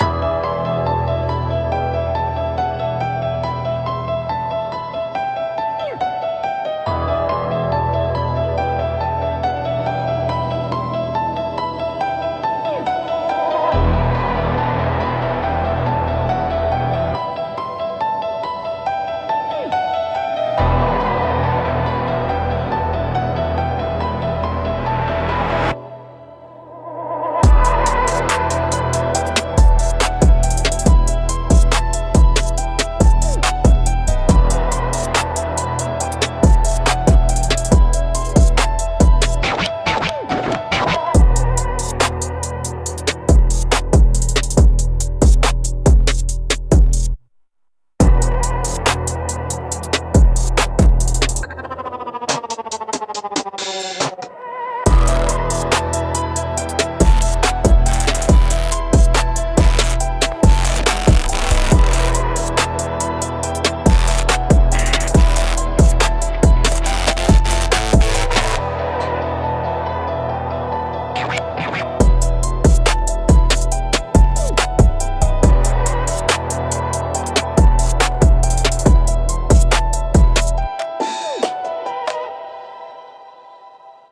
Instrumental - Graffiti Dreams